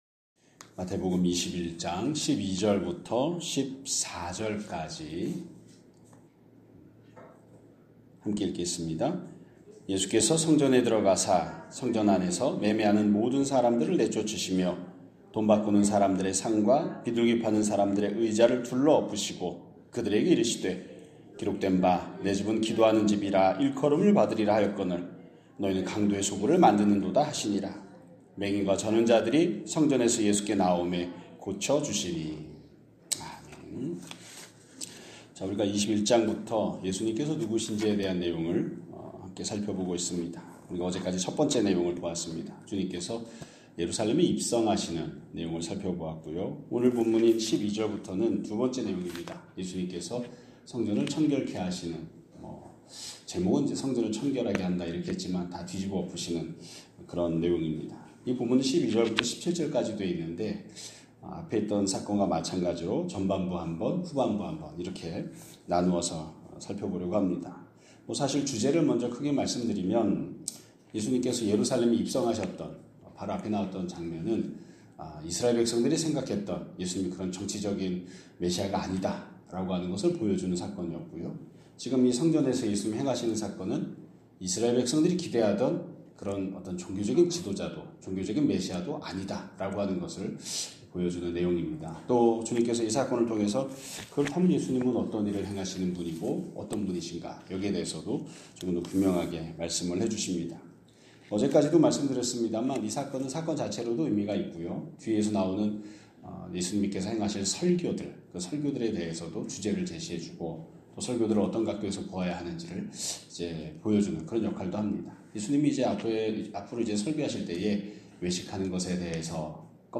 2026년 1월 23일 (금요일) <아침예배> 설교입니다.